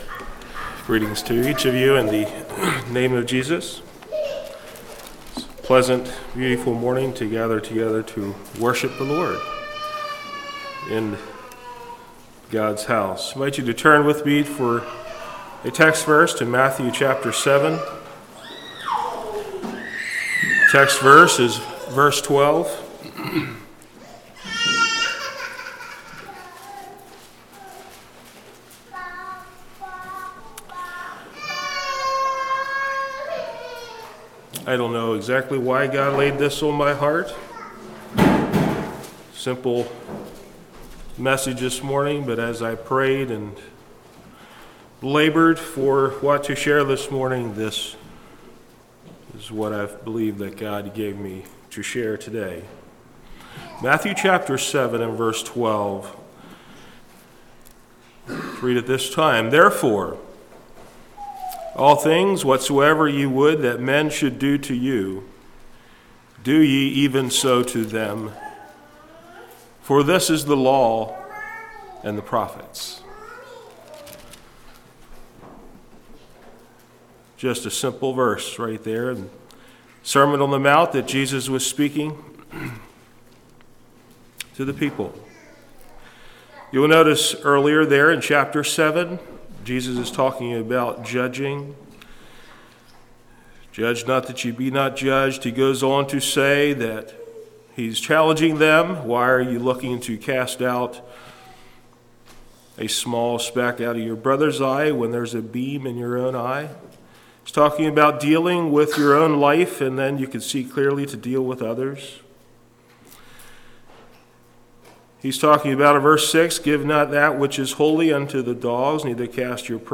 Play Now Download to Device The Golden Rule Congregation: Chapel Speaker